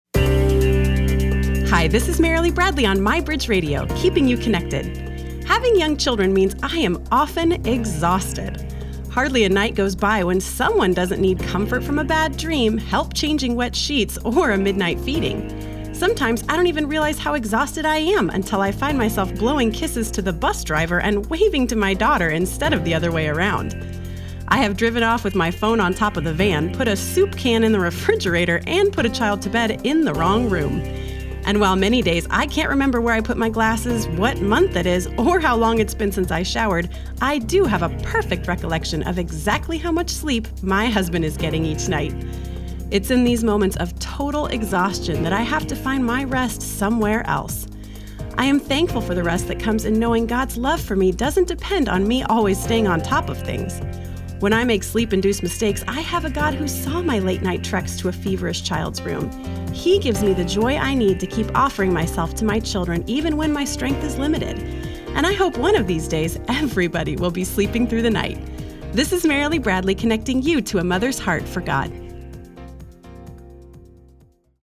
Here’s my radio spot on living in a season of sleep deprivation.